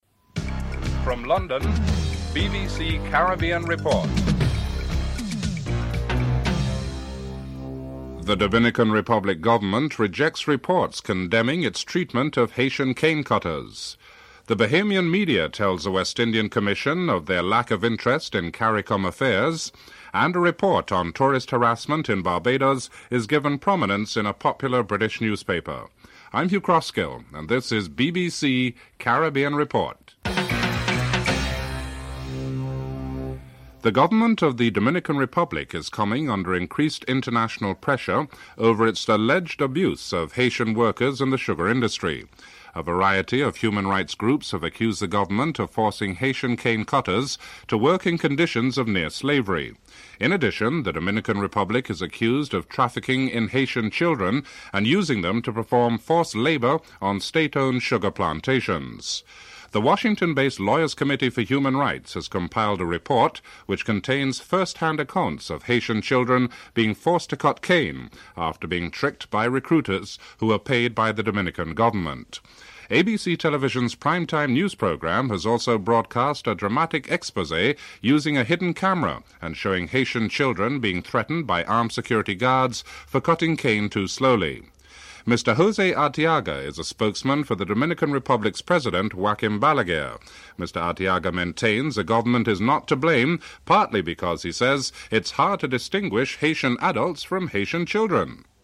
The British Broadcasting Corporation
1. Headlines (00:00-00:33)
6. Jonathan Agnew reports on the first day of the second test match between the West Indies and England, with the West Indies closing the day at 317 runs for 3 wickets (13:34-14:51)